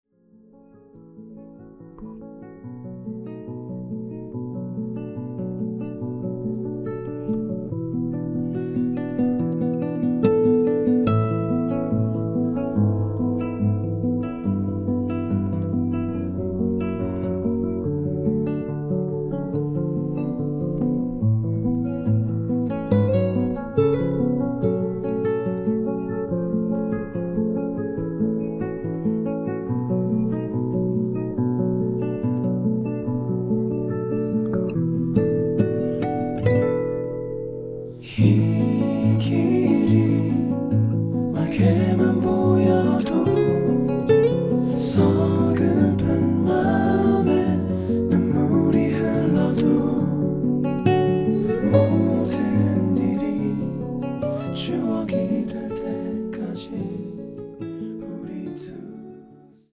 음정 원키 4:16
장르 가요 구분 Voice MR